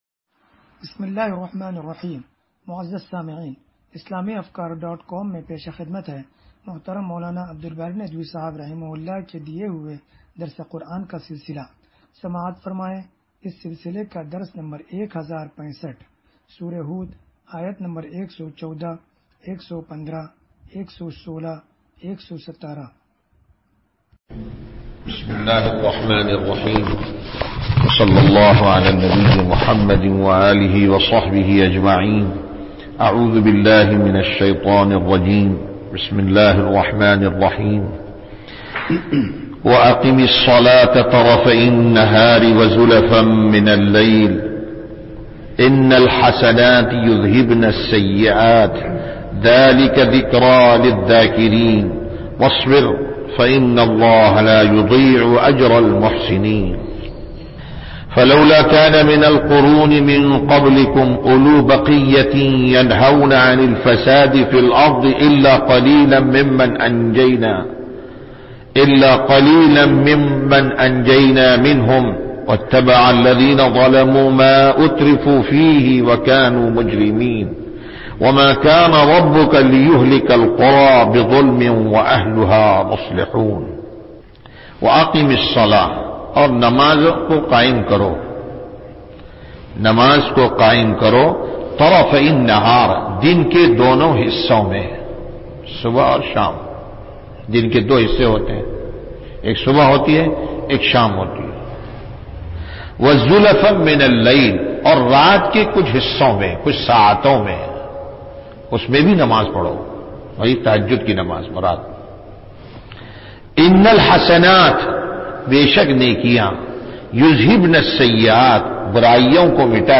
درس قرآن نمبر 1065